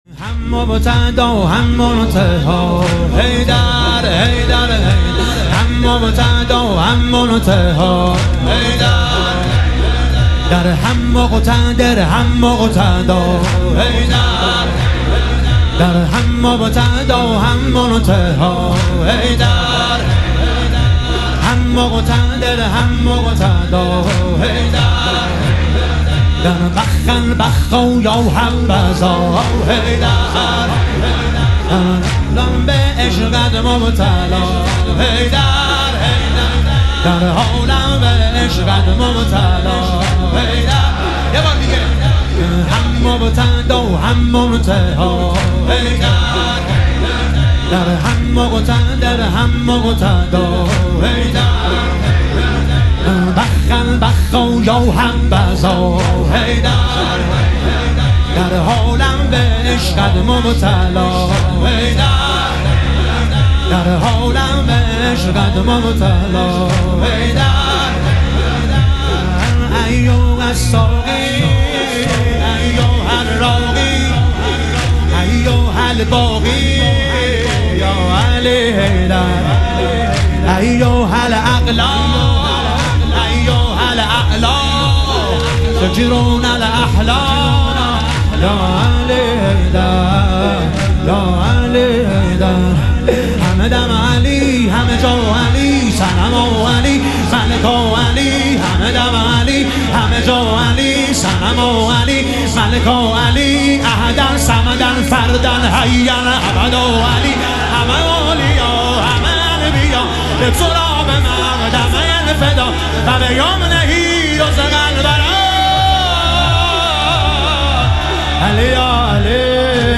شهادت امام جواد علیه السلام - واحد